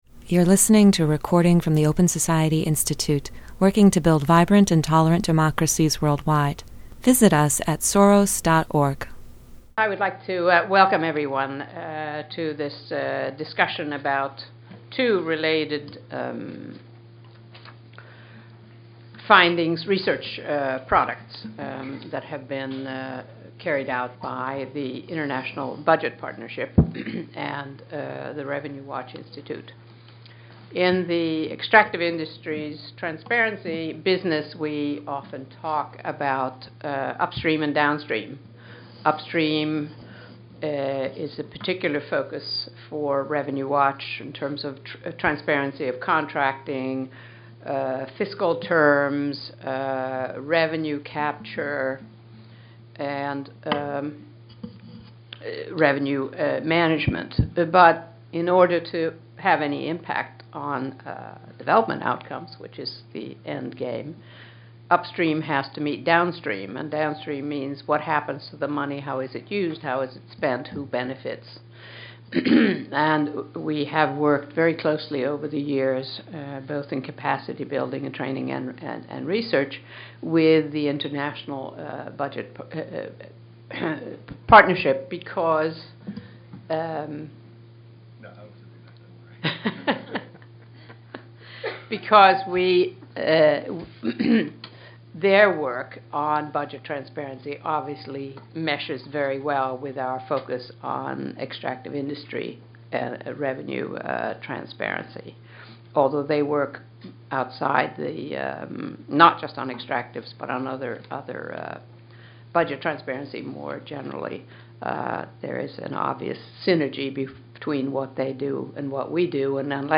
OSI grantee the Revenue Watch Institute cohosted a discussion on the links between poor performance in many resource-dependent countries and the lack of budget transparency and accountability.